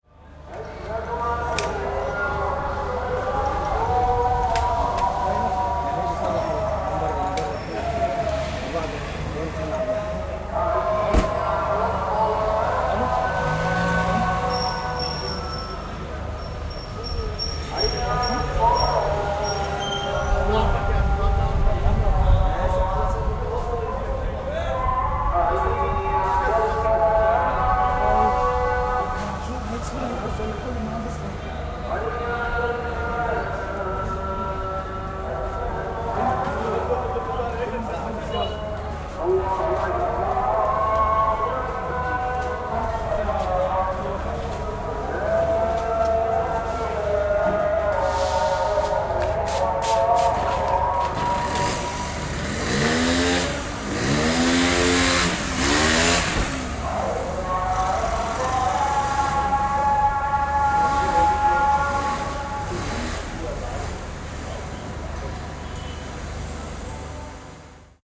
syriast-ambient_prayers.ogg